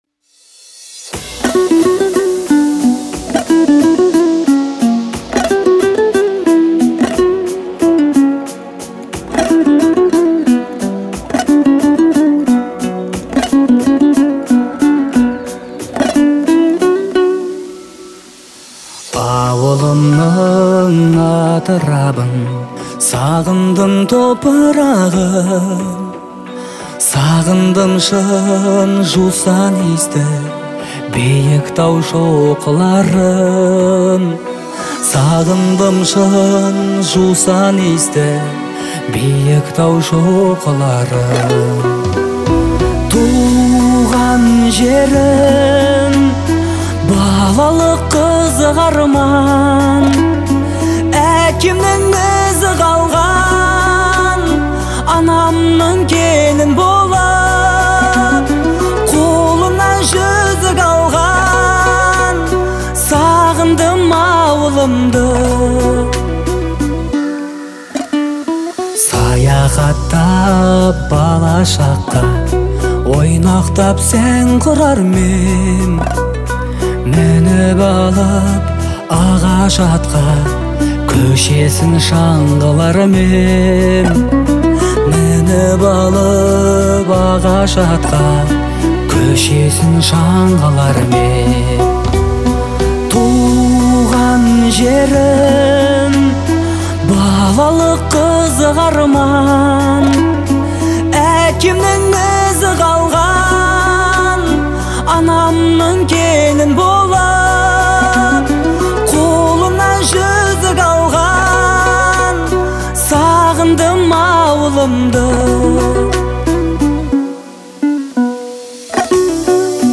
это трогательная песня в жанре народной музыки